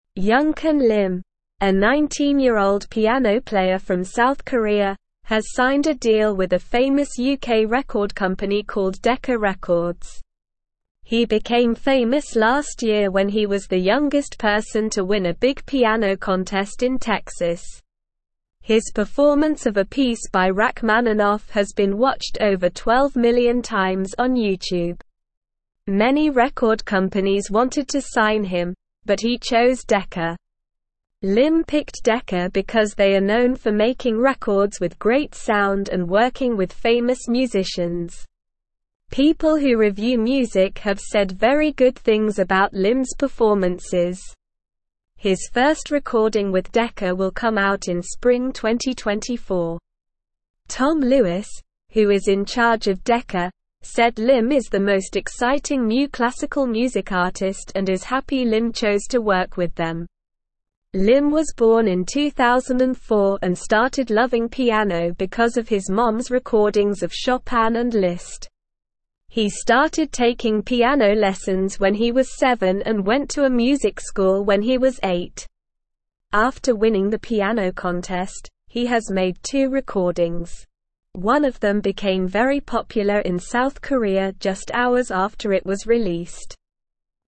Slow
English-Newsroom-Lower-Intermediate-SLOW-Reading-Young-Korean-Pianist-Signs-with-Famous-Music-Company.mp3